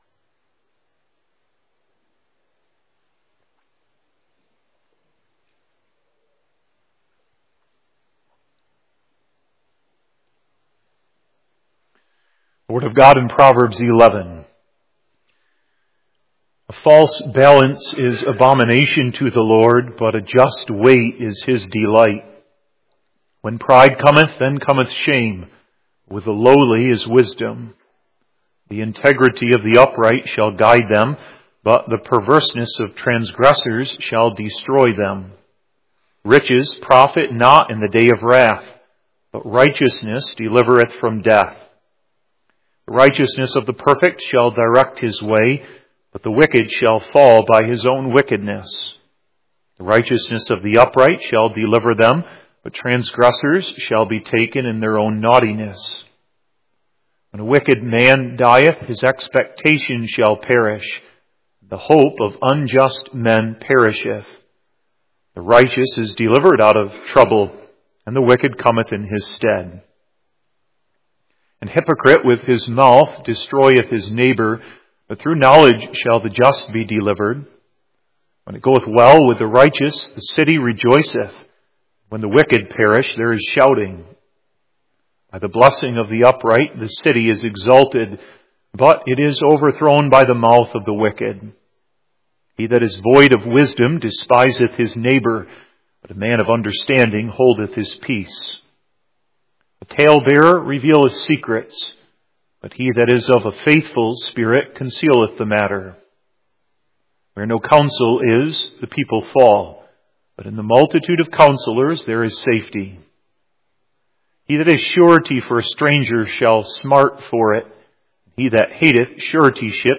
🔥 The Digital Gospel Minister — Bold. Anointed. Unapologetic. 🔥